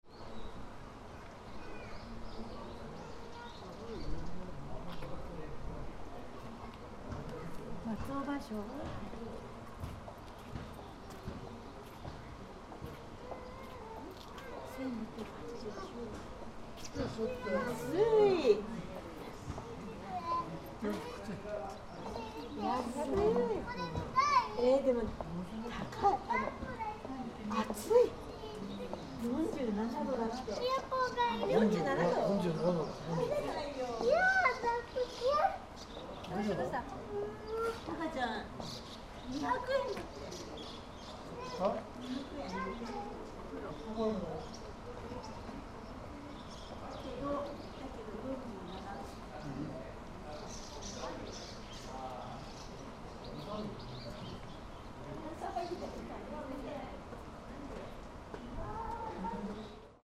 Iizaka Hot Spring: 27 April, 2024
In front of "Sabako yu" at Iizaka Hot Spring